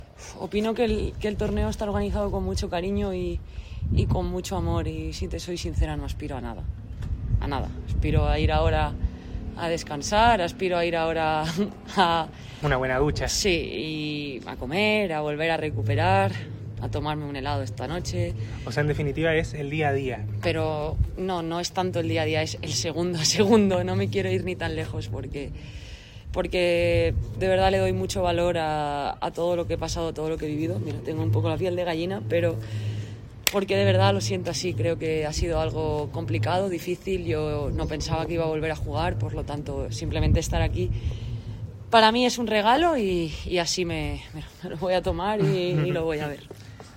Tras su triunfo, Sara Sorribes conversó en exclusiva con Chicureo Hoy y compartió las sensaciones de su retorno al circuito.
Escucha parte de lo que comentó la jugadora aquí: